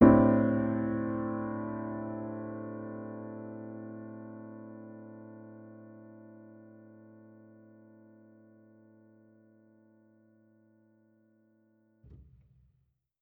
Index of /musicradar/jazz-keys-samples/Chord Hits/Acoustic Piano 2
JK_AcPiano2_Chord-Am11.wav